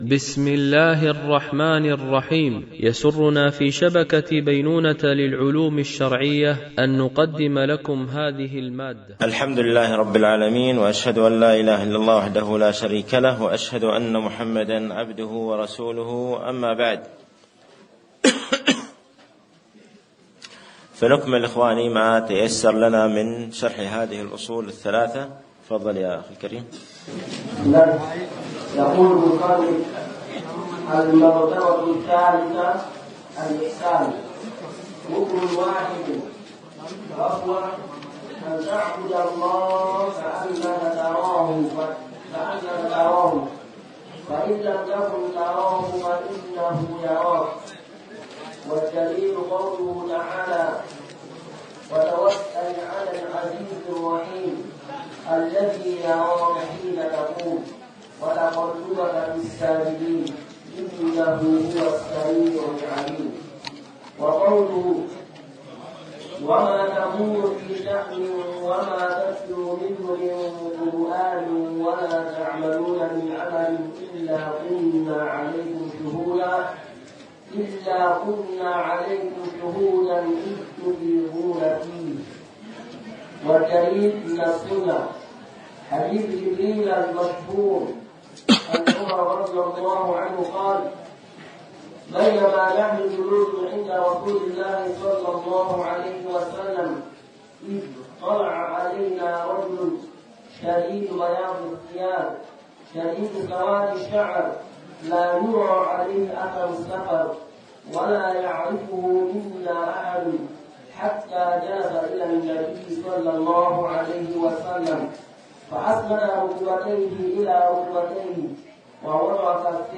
شرح الأصول الثلاثة ـ الدرس 10
MP3 Mono 44kHz 96Kbps (VBR)